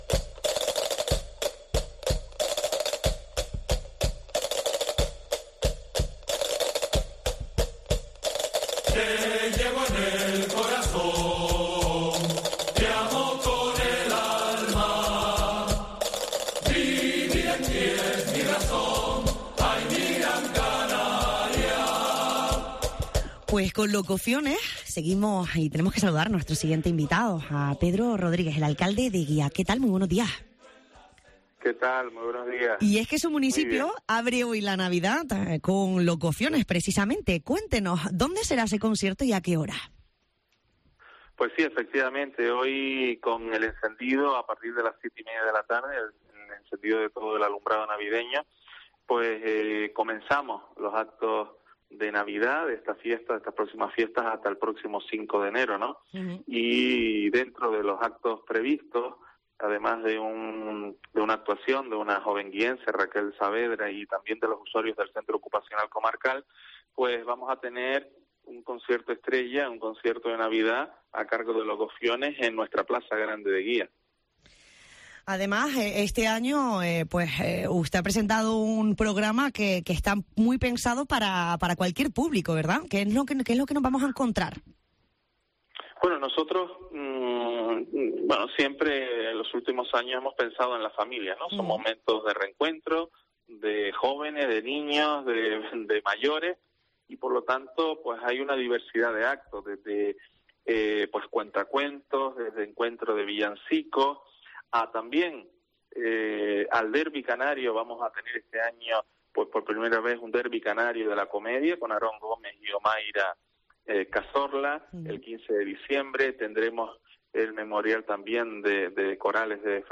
Entrevista a Pedro Rodríguez, alcalde de Santa María de Guía